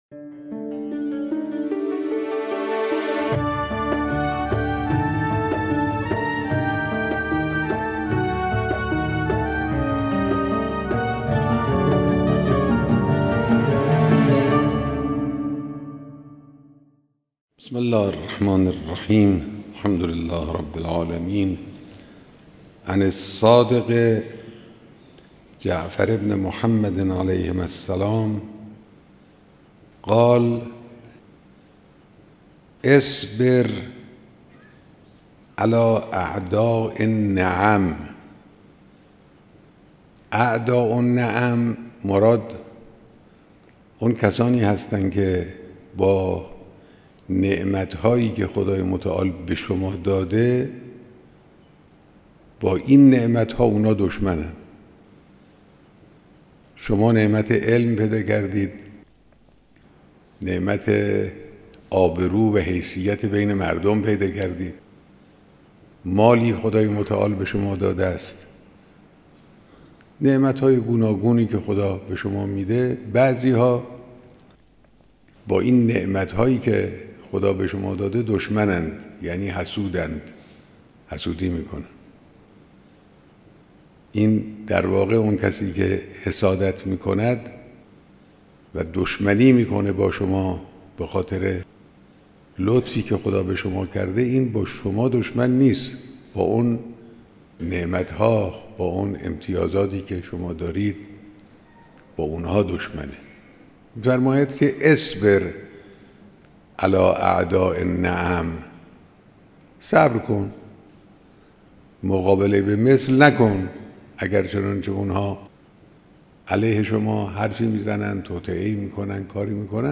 شرح احادیث اخلاقی توسط رهبر/ توجه به صبر